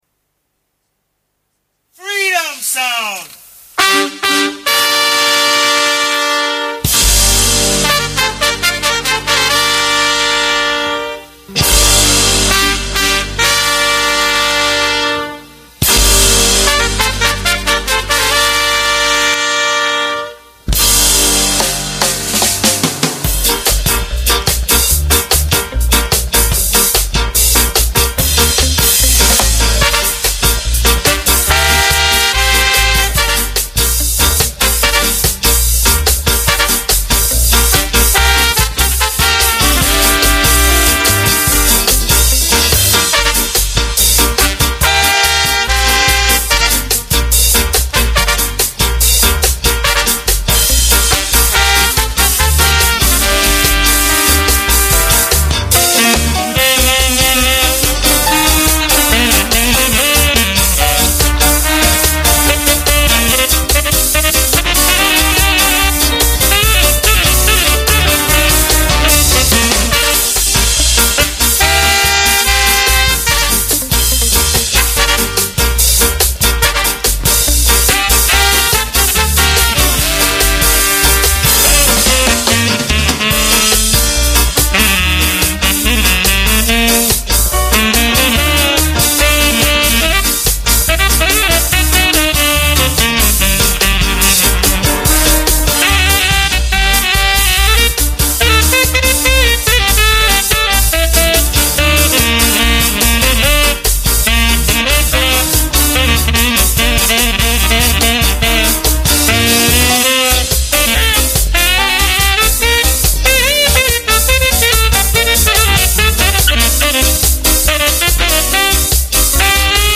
Aquesta setmana hem preparat un programa especial dedicat a l’Skinhead Reggae, amb una selecció del què considerem alguns dels millors temes d’aquest gènere adoptat per la classe treballadora anglesa de finals dels 60 arrel del contacte amb la població immigrant originària de Jamaica.